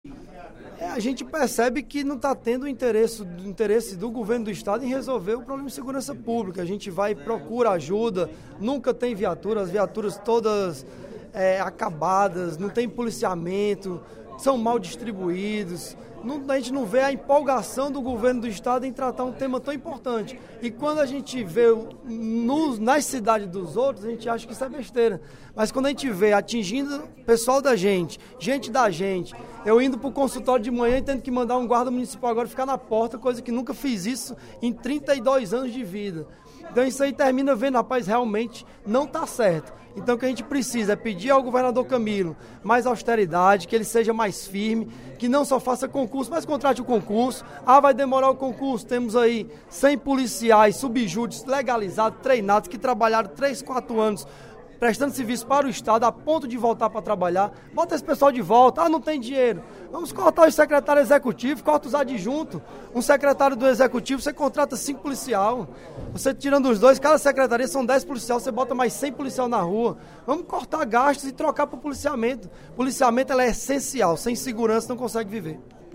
O deputado Bruno Gonçalves (PEN) avaliou, durante o primeiro expediente da sessão plenária desta quinta-feira (23/03), a segurança pública no município do Eusébio. De acordo com o parlamentar, as medidas adotadas pela Secretaria da Segurança Pública e Defesa Social (SSPDS) são ineficientes, e a população está apavorada com o crescimento da violência.